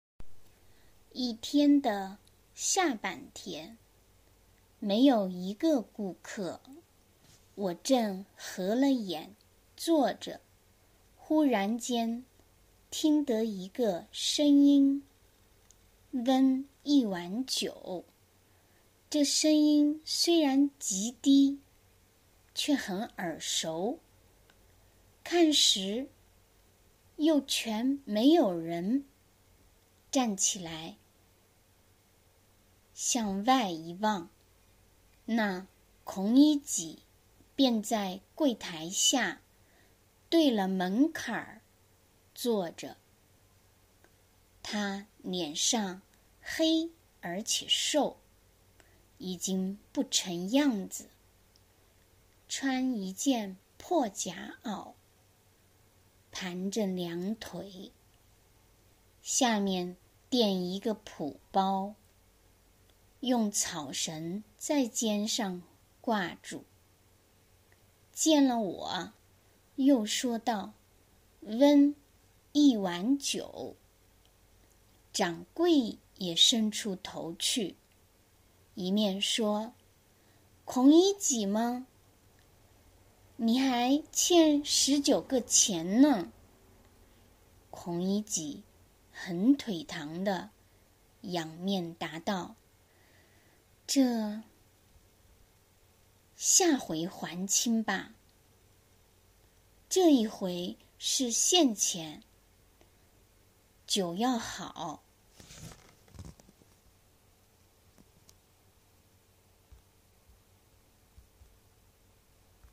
では、最初に第11回目のピンイン、朗読と翻訳例です。
《発音》